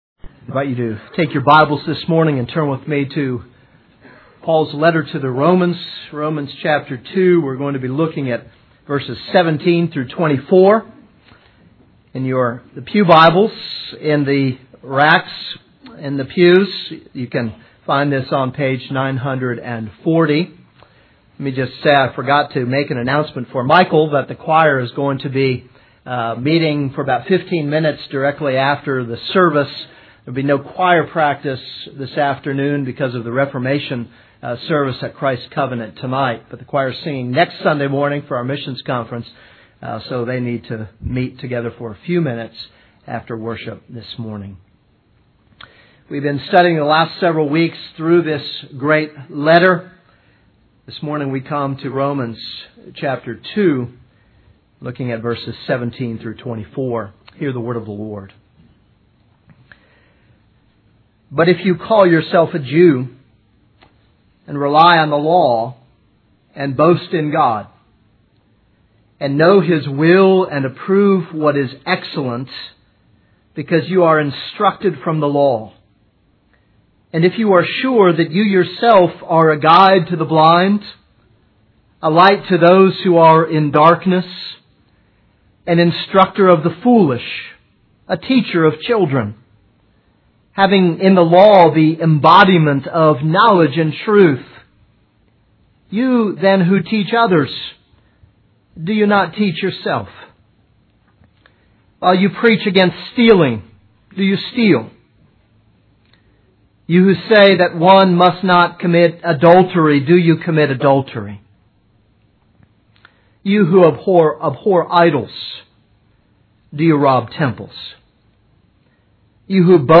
This is a sermon on Romans 2:17-24.